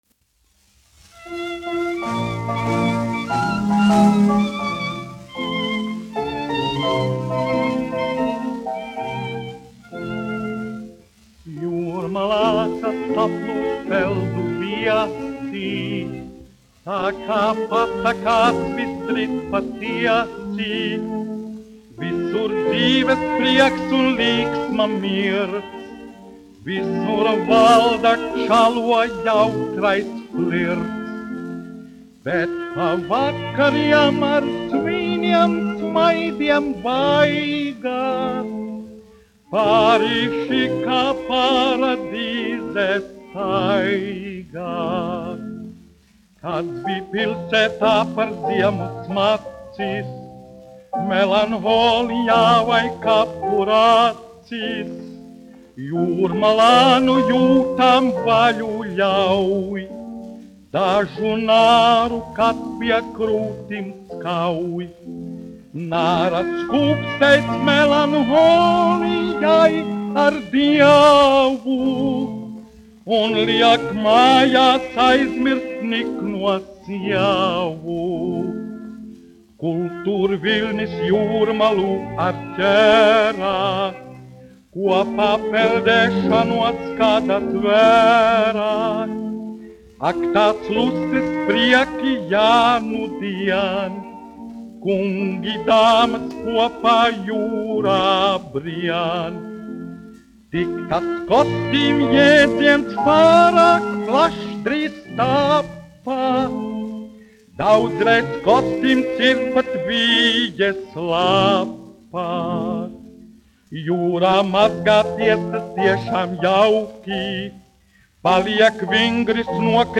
1 skpl. : analogs, 78 apgr/min, mono ; 25 cm
Populārā mūzika -- Latvija
Humoristiskās dziesmas
Skaņuplate
Latvijas vēsturiskie šellaka skaņuplašu ieraksti (Kolekcija)